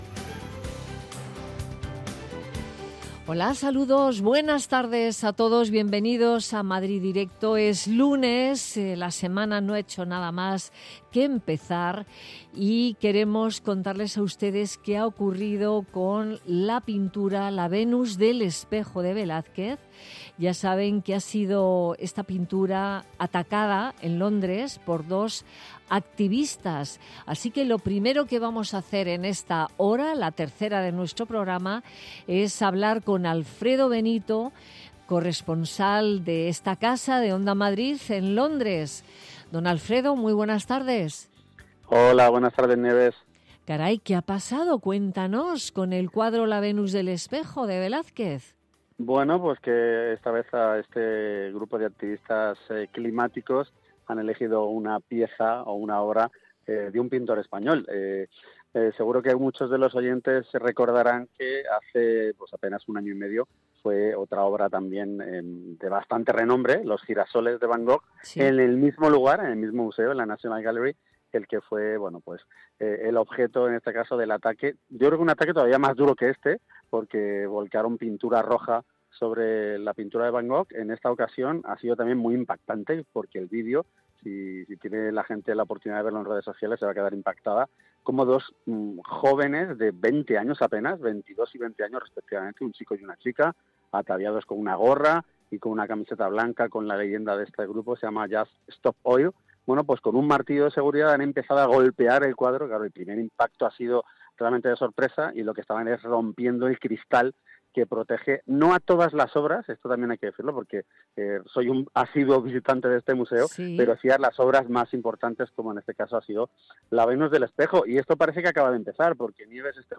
Nieves Herrero se pone al frente de un equipo de periodistas y colaboradores para tomarle el pulso a las tardes. Tres horas de radio donde todo tiene cabida: análisis de la actualidadcultura, ciencia, economía... Te contamos todo lo que puede preocupar a los madrileños.